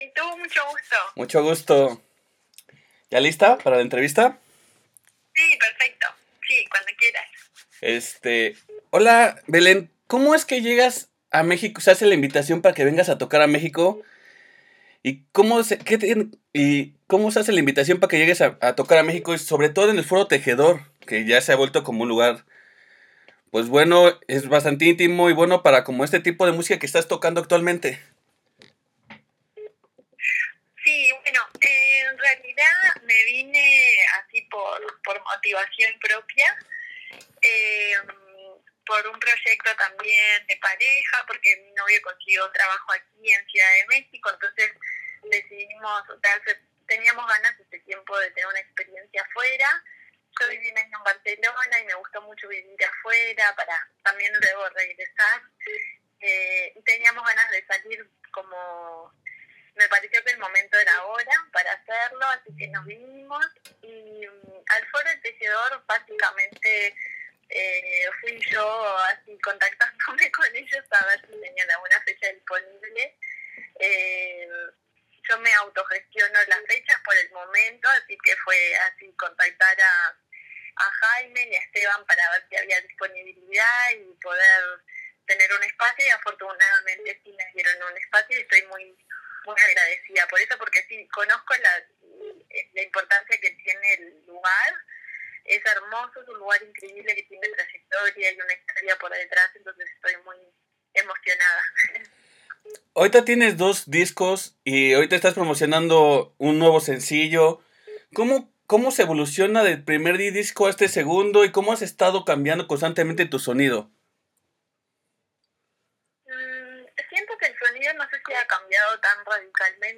Tuvimos la oportunidad de hablar con ella, antes de su presentación, y donde nos platico sobre sus dos discos, que estará tocando y como fue puliendo su sonido, como ve el folk tanto en México como Uruguay, y lo que podemos esperar a futuro.